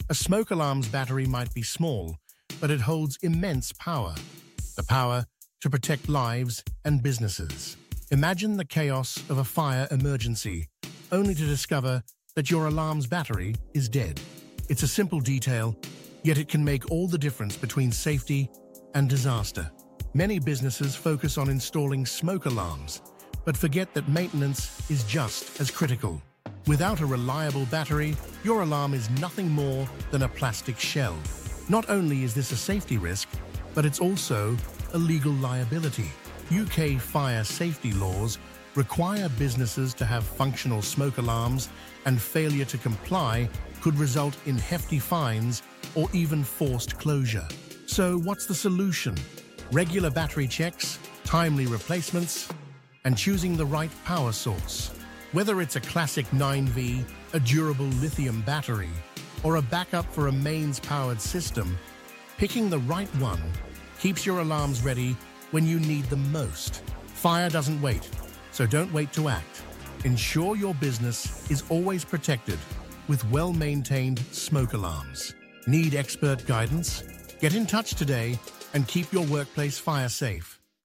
voicesmokealarm.mp3